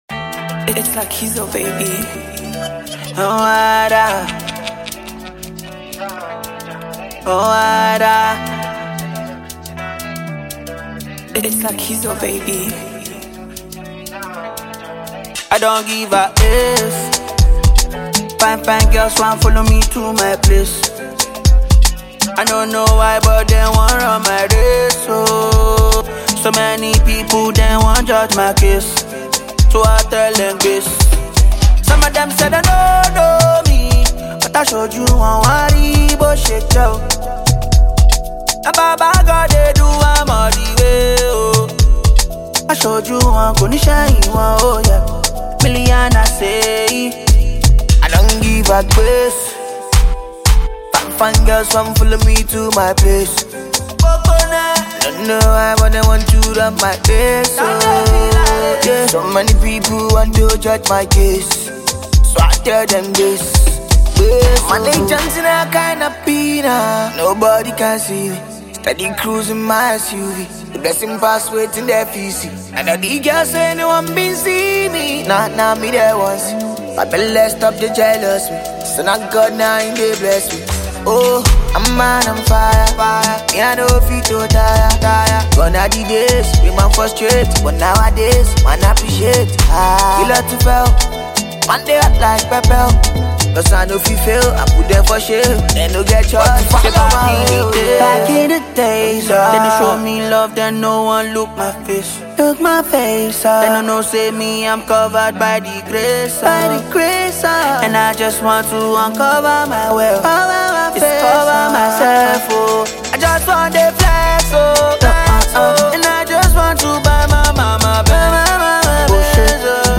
talented Nigerian singers